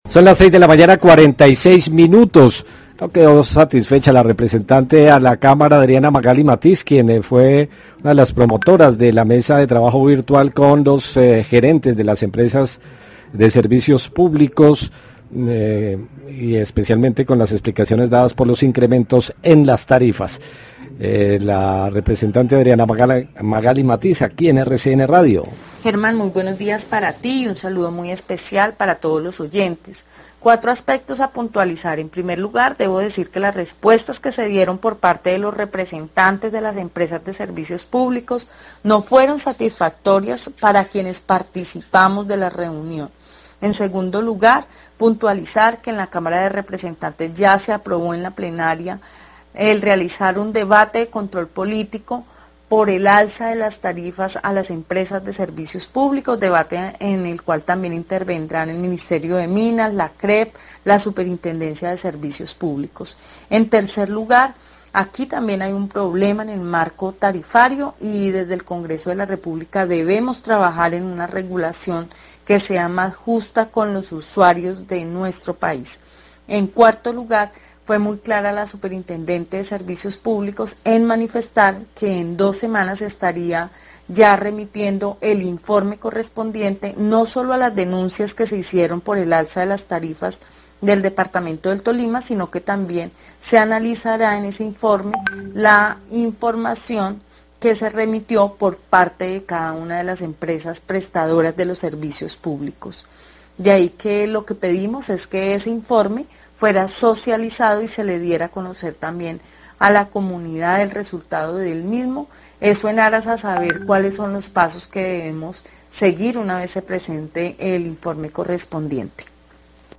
Radio
Entrevista al representante a la Cámara